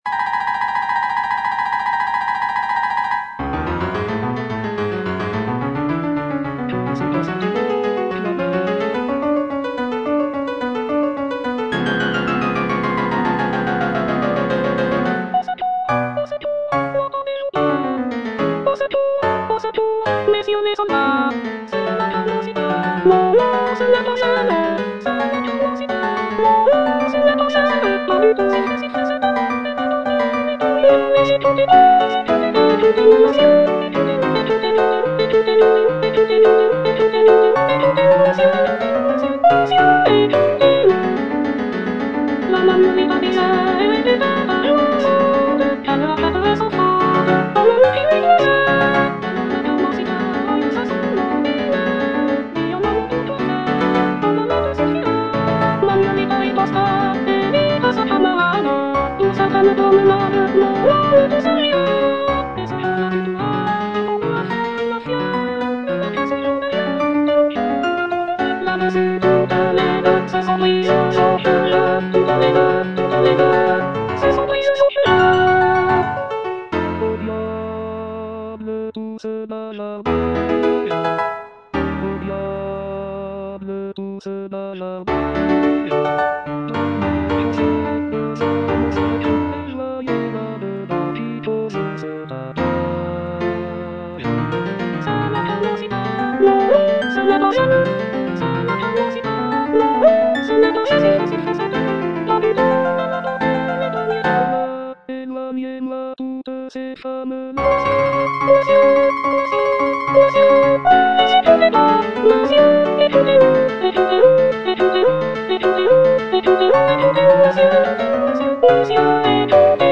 G. BIZET - CHOIRS FROM "CARMEN" Que se passe-t-il donc là-bas? (soprano III) (Emphasised voice and other voices) Ads stop: auto-stop